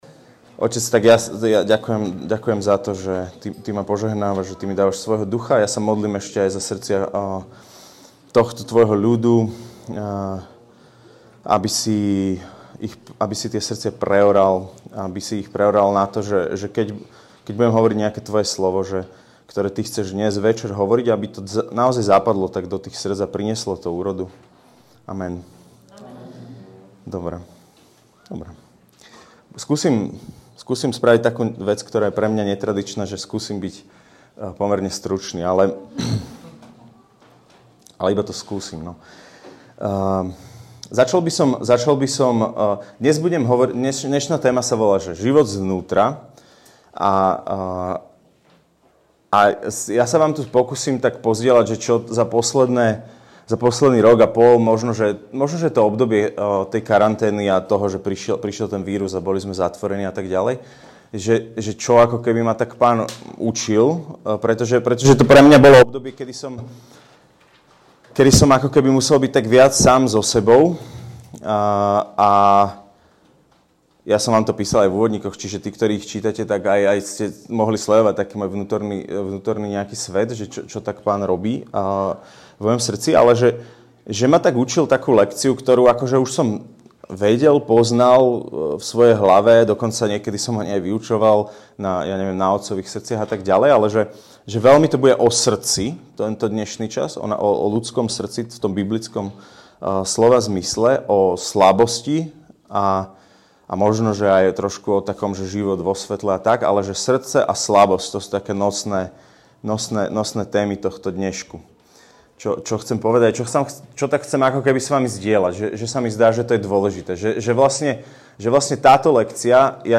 Jedno z kľúčových vyučovaní z tohtoročnej duchovnej obnovy našej komunity (Kláštorovica). To ako prežívame život závisí viac od stavu nášho srdca ako od okolností v ktorých sa nachádzame. Naše srdcia sú miestom Stretnutia s Bohom.